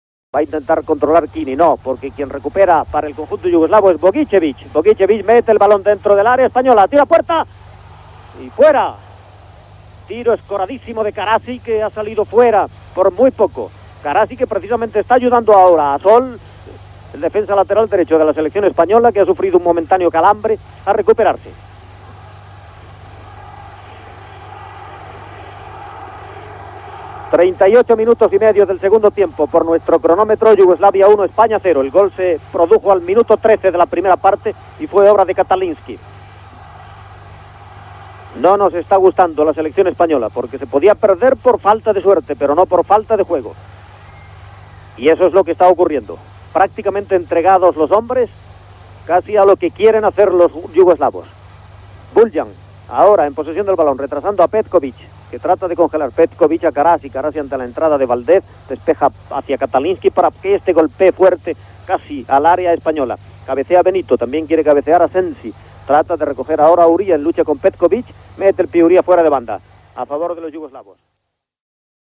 Narració de jugades de la segona part del partit de futbol entre les seleccions d'Espanya i Iugoslàvia per a la classificació del Mundial d'Alemanya Federal de 1974 (El partit va acabar amb un empat 2-2)
Esportiu